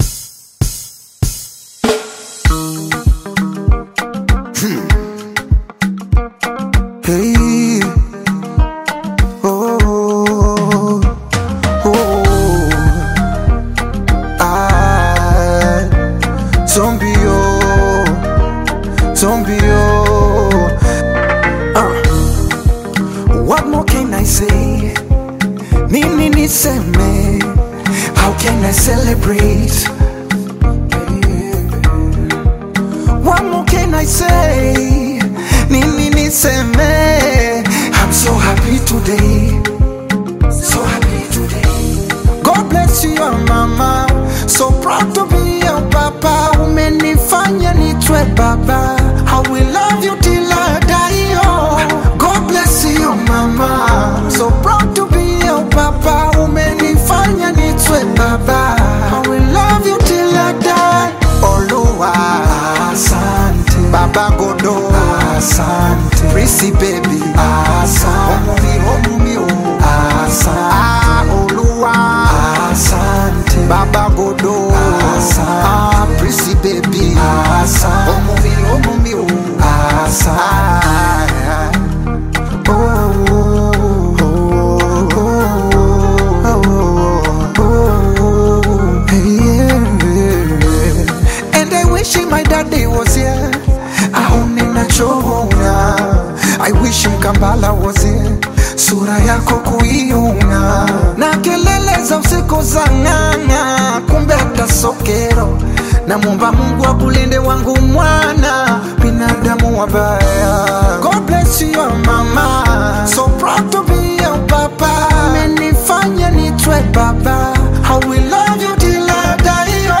Tanzanian R&B